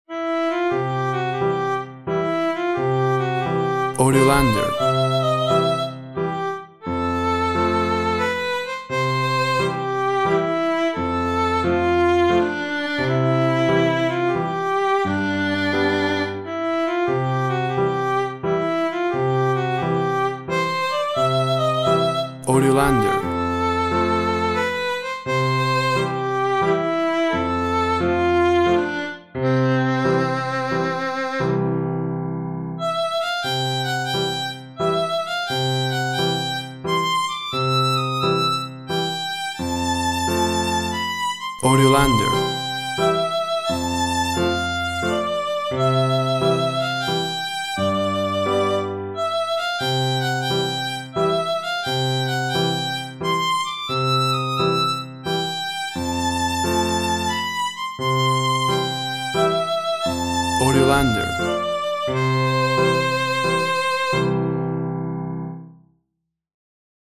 Tempo (BPM) 88